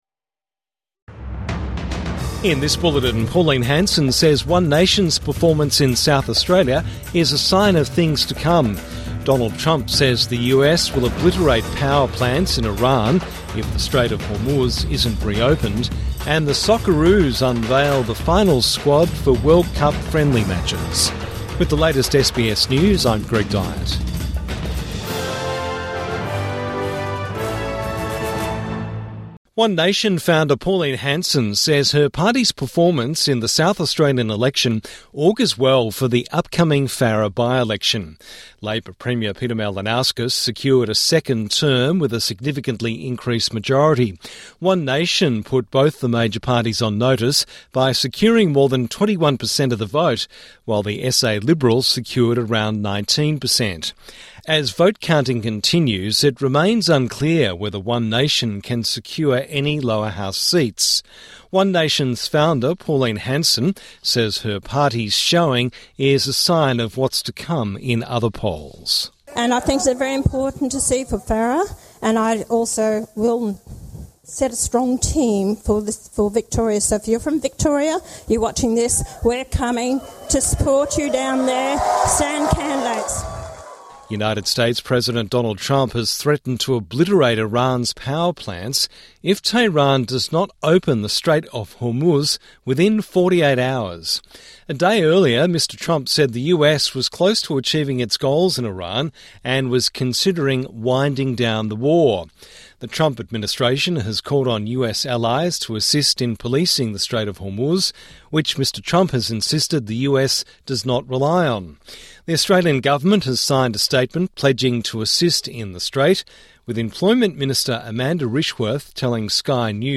Hanson welcomes One Nation's performance in South Australia | Evening News Bulletin 22 March 2026